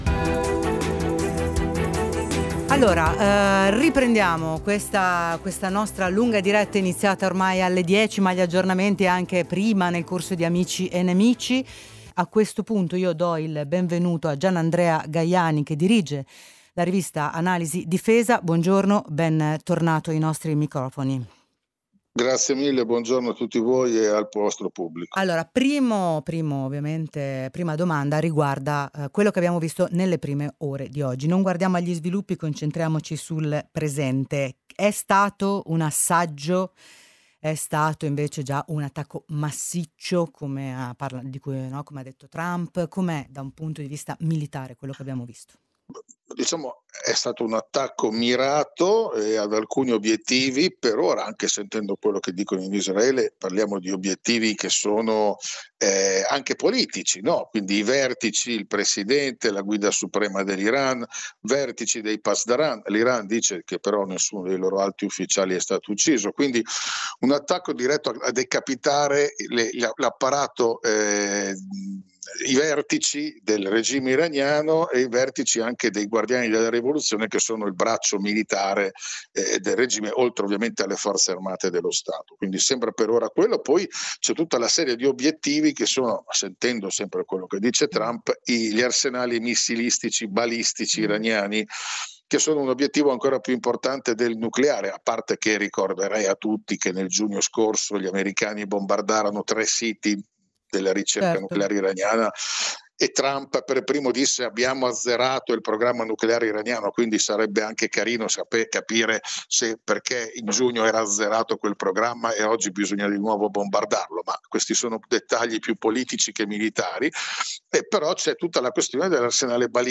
Intervista realizzata la mattina del 28 febbraio